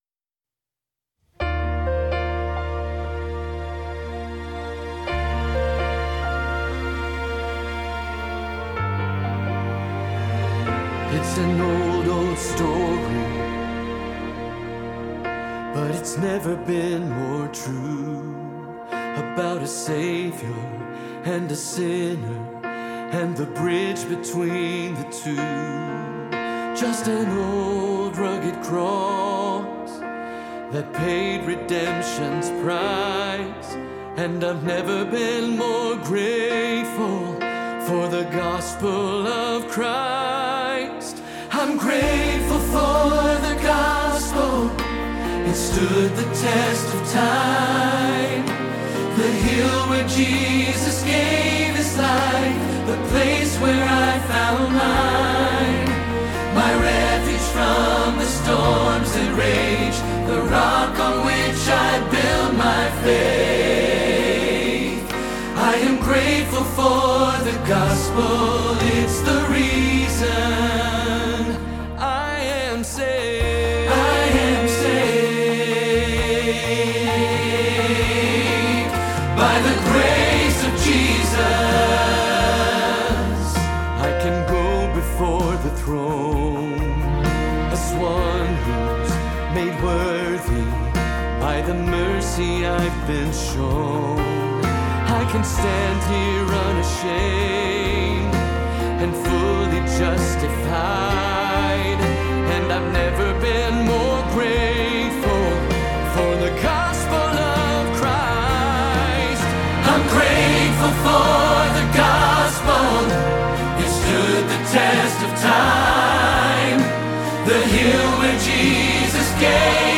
Grateful for the Gospel – Tenor Hilltop Choir
05-Grateful-for-the-Gospel-Tenor-Rehearsal-Track.mp3